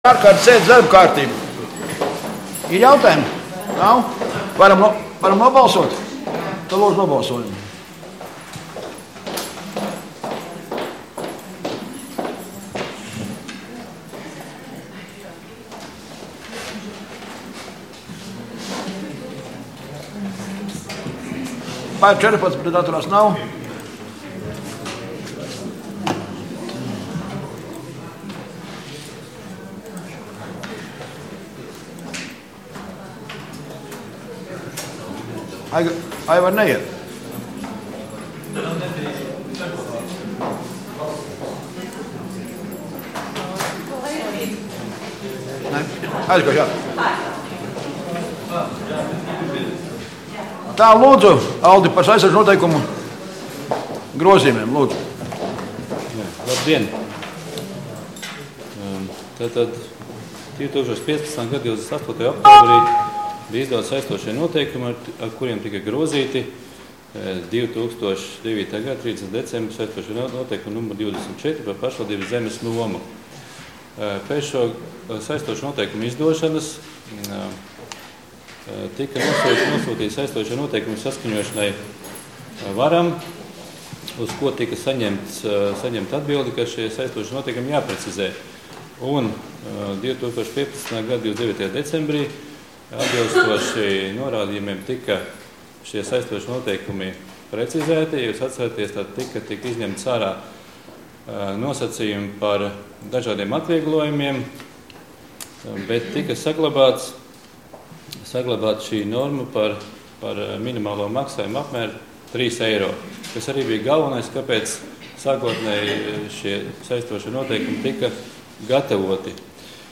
Domes ārkārtas sēde Nr. 2